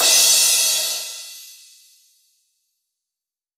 PCRASH 04.wav